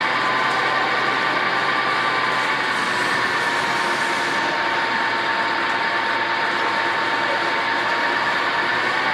agricultural-tower-arm-extend-loop.ogg